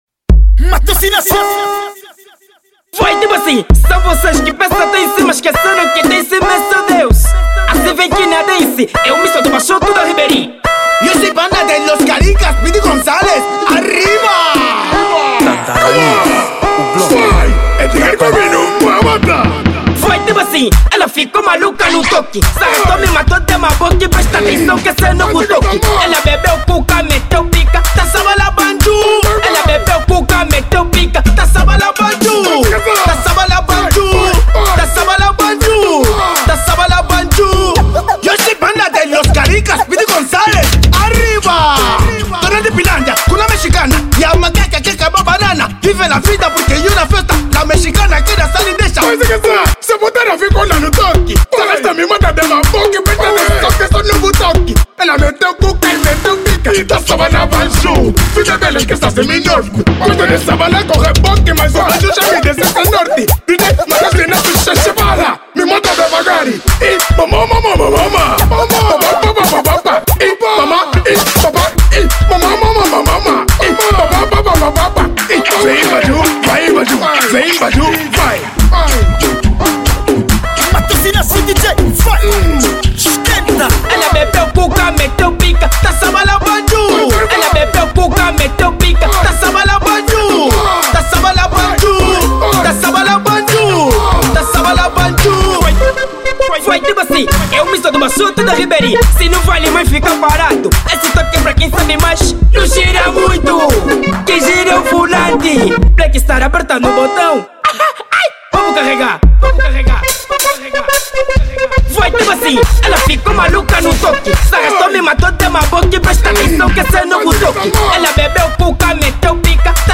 Estilo : Afro House